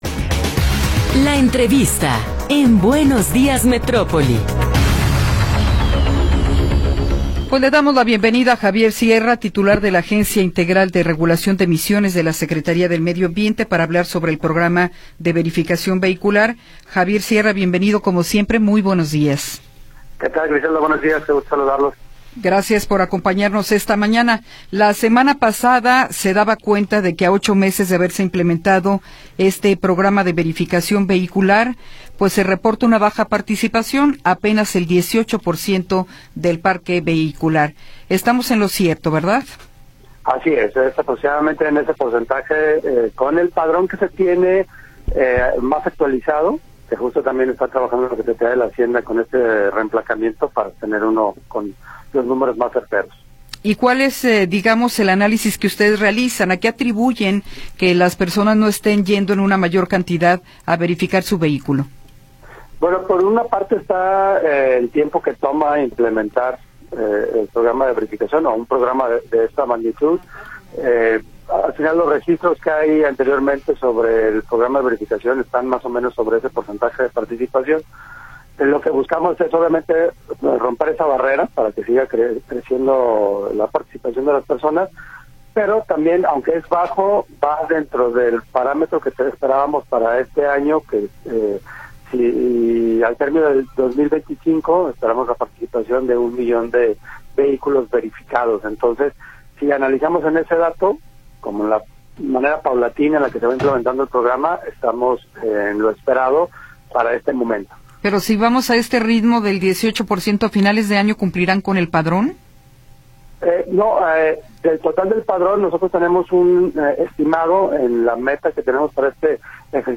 Entrevista con Javier Sierra Moreno